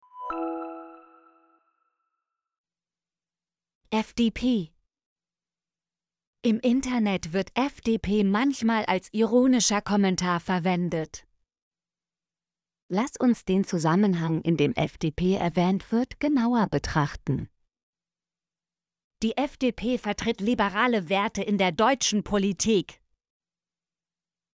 fdp - Example Sentences & Pronunciation, German Frequency List